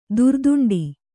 ♪ durduṇḍi